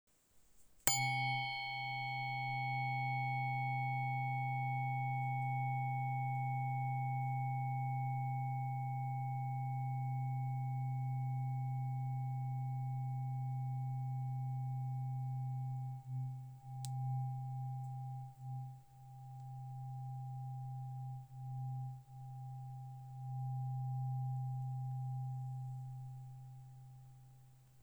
Terapeutická ladička 128 Hz hliník CZ
Relaxace a meditace: Zvuk terapeutické ladičky 128 Hz je často považován za hluboce relaxační a uklidňující.
Hliníková varianta je pro zvukové ošetření nejlepší - její zvuk je dobře slyšitelný, ohraničený a výrazný.
• Frekvence: 128 Hz
Terapeutické ladičky z naší české dílny jsou vyrobené z prvotřídního hliníku, který poskytuje výjimečně čistou a dlouhotrvající vibraci.